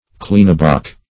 Search Result for " kleeneboc" : The Collaborative International Dictionary of English v.0.48: Kleeneboc \Kleene"boc`\ (kl[=e]n"b[o^]k`), n. [D. kleen little, small + bok buck.]